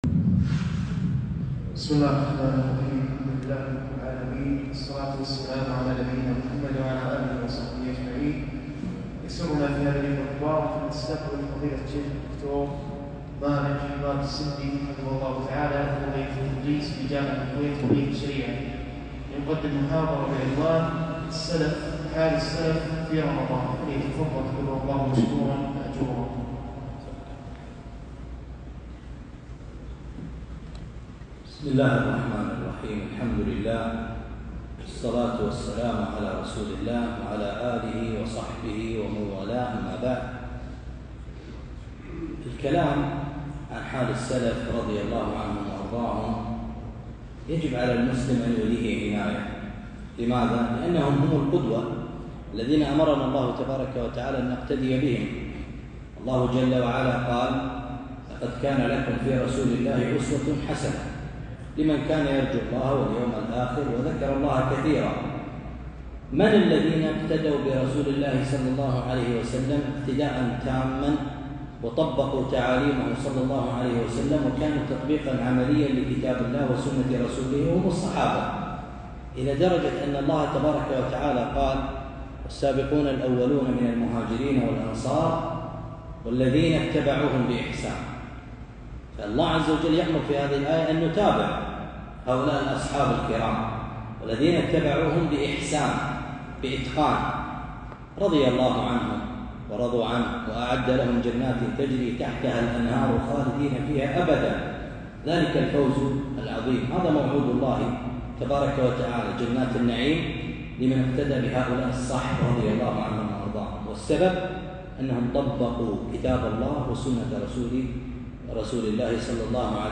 محاضرة - حال السلف في رمضان